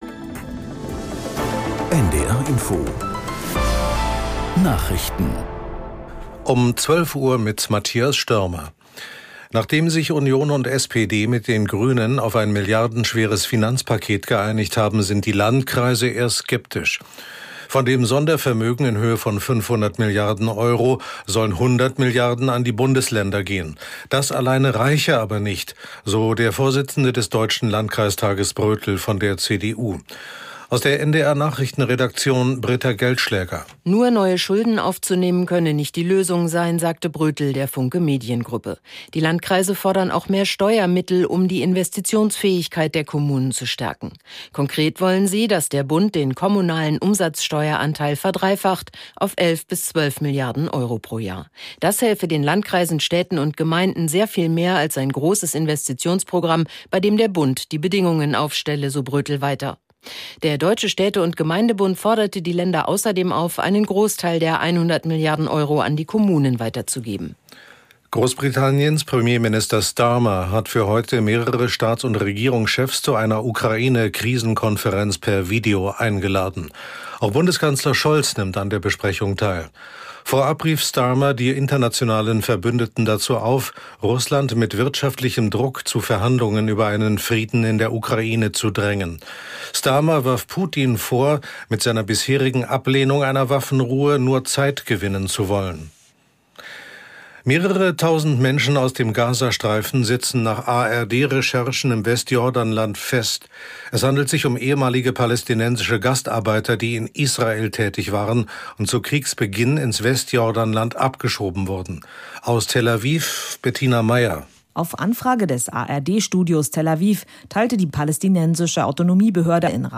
Nachrichten für den Norden.